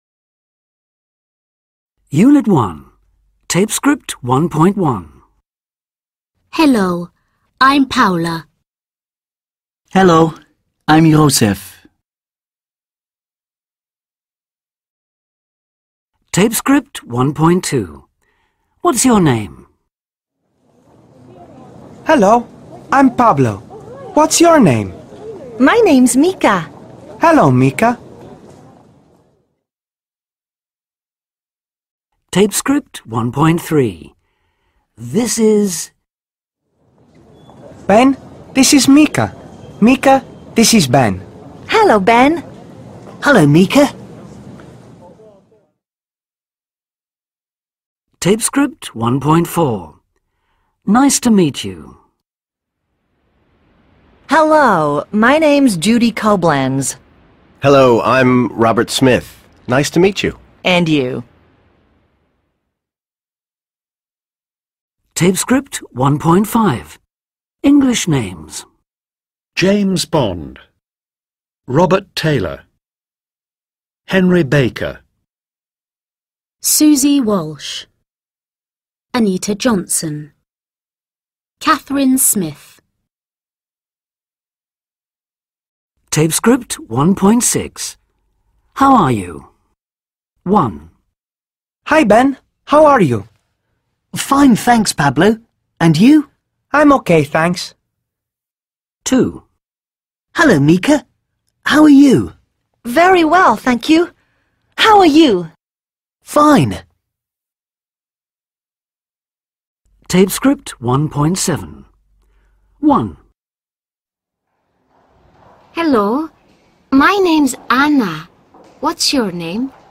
كنز ثمين لمحبي اللغة الإنجليزية بشكل عام واللهجة البريطانية بشكل خاص